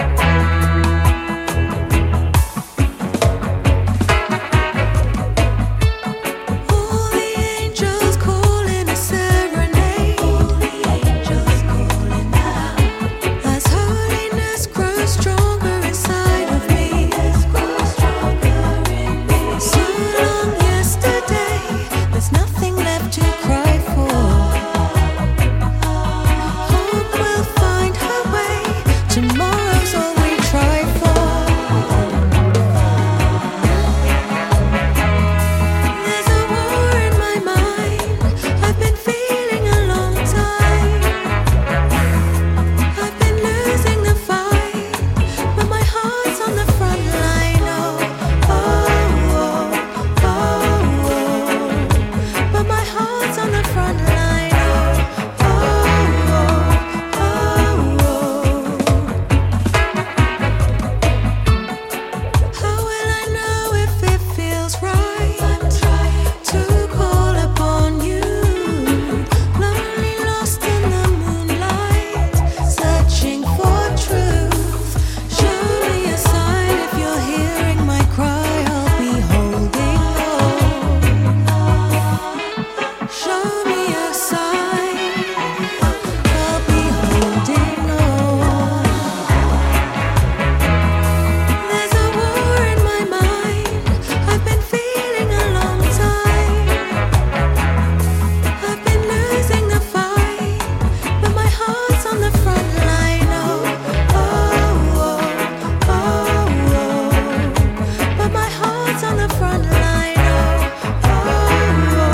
rootsy chugging
The deep roots flavour